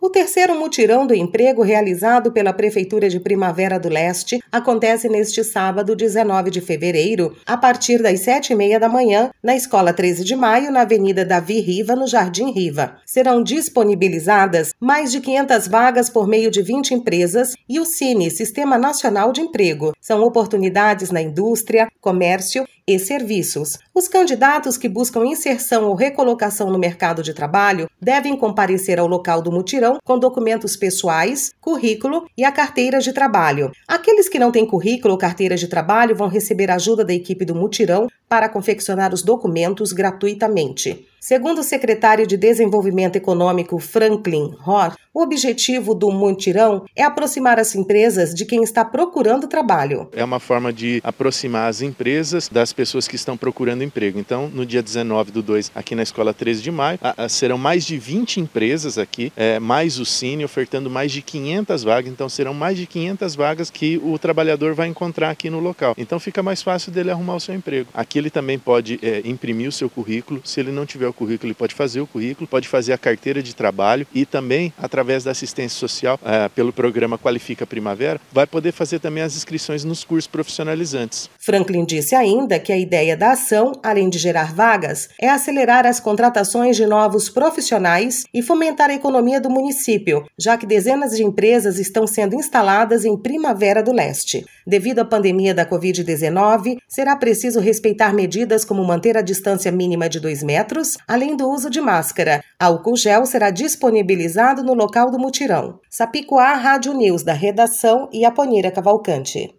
Sonora - secretário Franklin Rohr